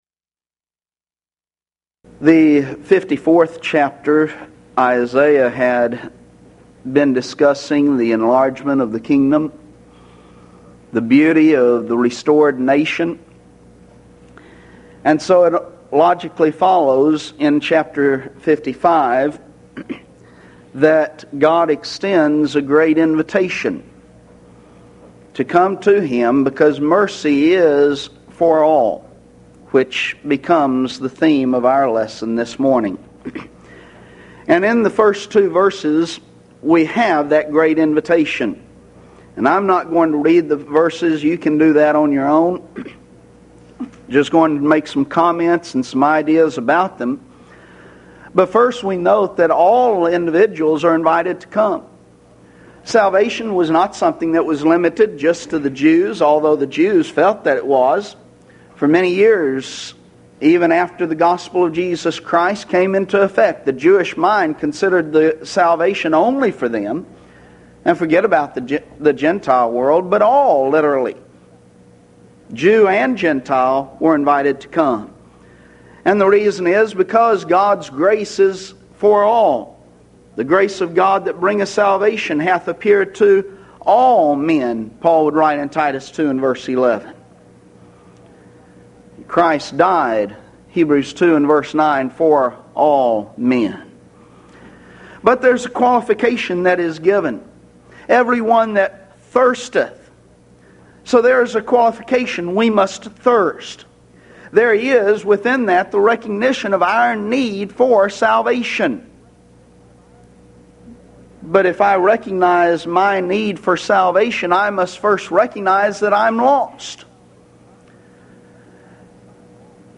Event: 1996 HCB Lectures Theme/Title: The Book Of Isaiah - Part II
If you would like to order audio or video copies of this lecture, please contact our office and reference asset: 1996Houston24